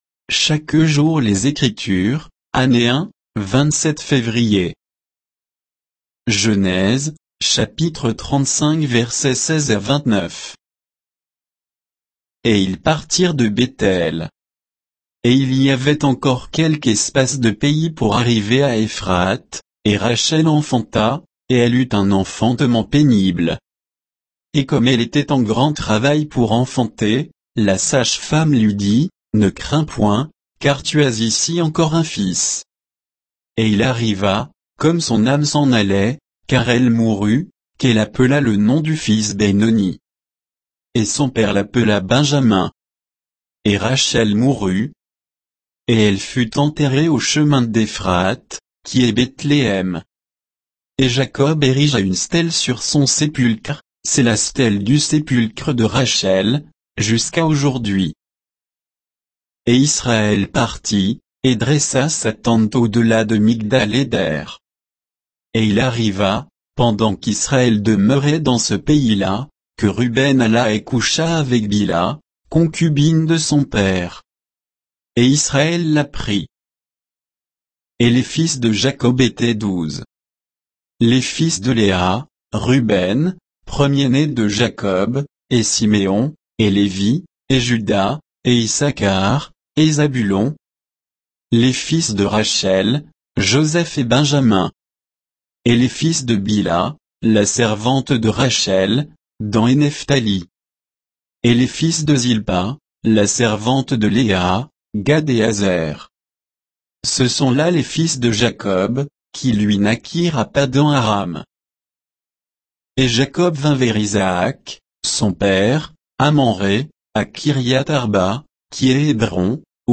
Méditation quoditienne de Chaque jour les Écritures sur Genèse 35